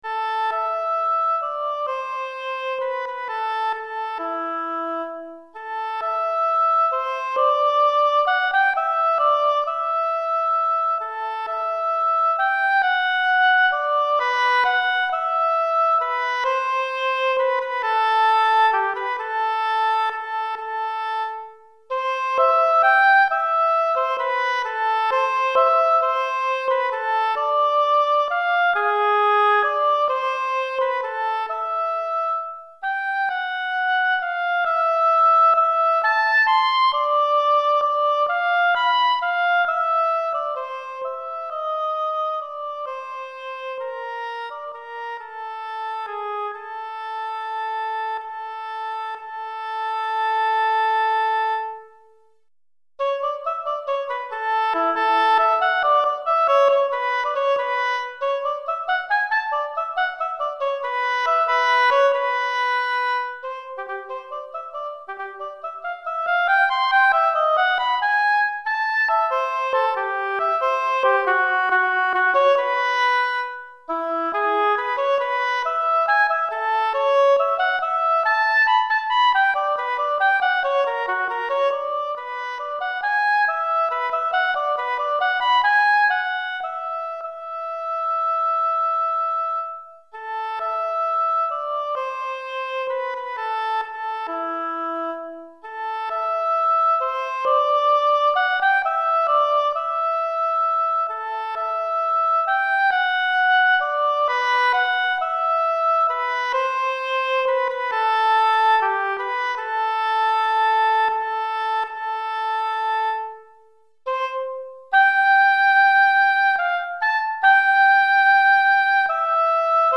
Pour hautbois solo
Hautbois solo